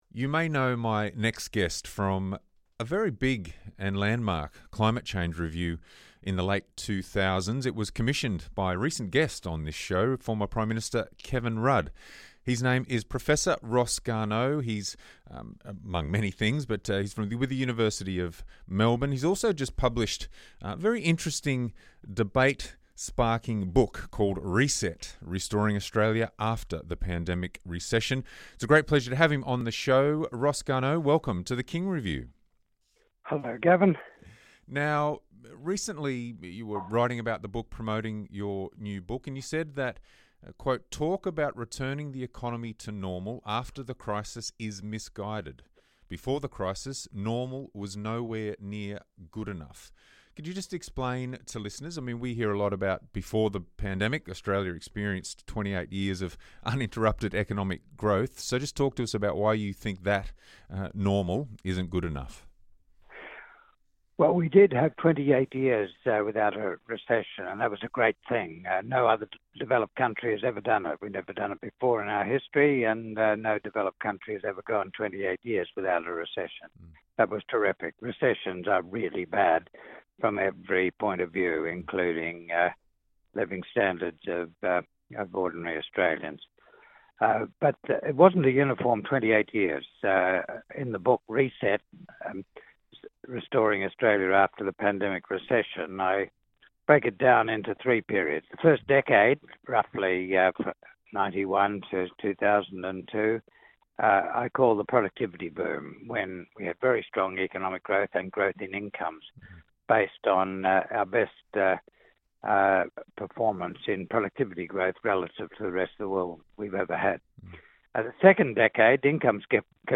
chat with Professor Ross Garnaut